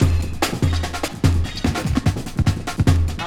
DRMZ N BS1.wav